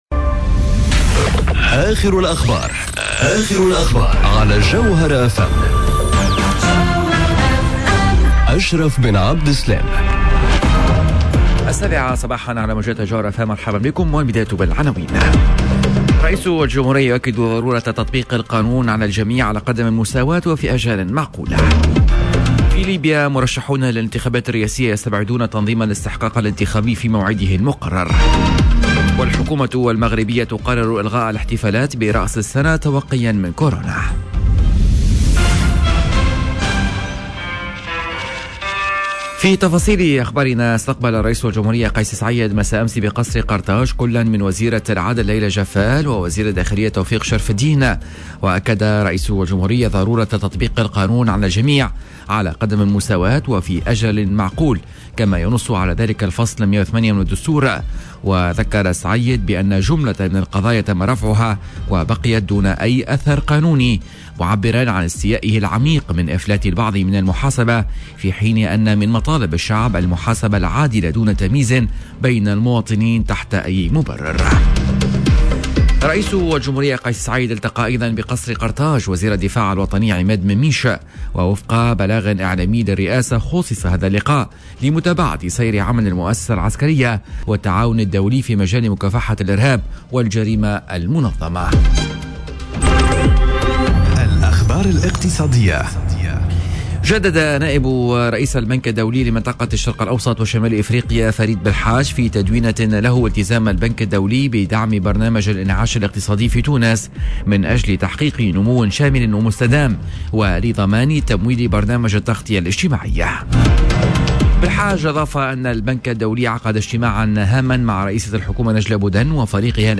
نشرة أخبار السابعة صباحا ليوم الإثنين 21 ديسمبر 2021